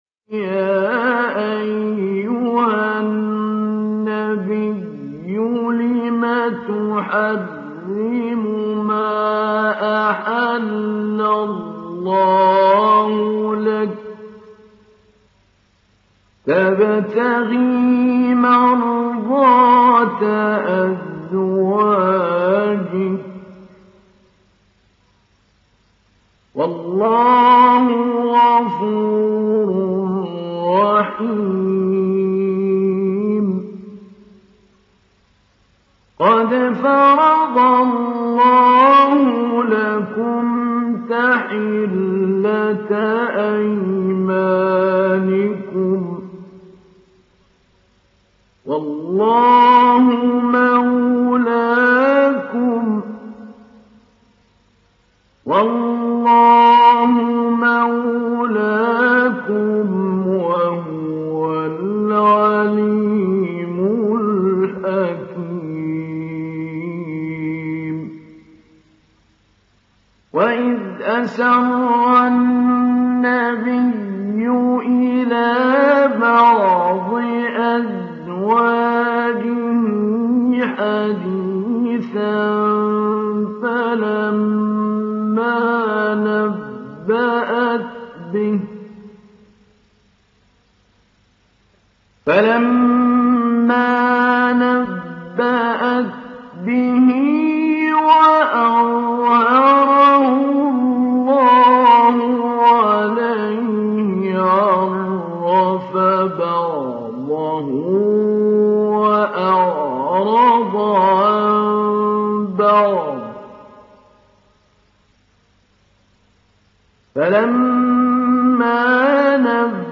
Download Surat At Tahrim Mahmoud Ali Albanna Mujawwad